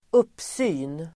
Uttal: [²'up:sy:n]
uppsyn.mp3